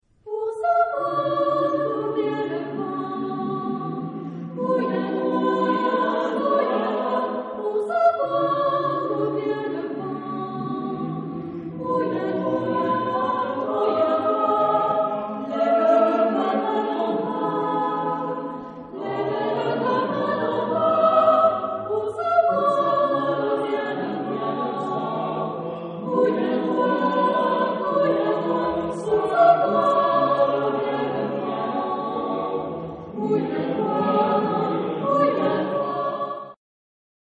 Genre-Style-Form: Secular ; Poetical song ; Waltz
Mood of the piece: lively
Type of Choir: SATB  (4 mixed voices )
Tonality: polytonal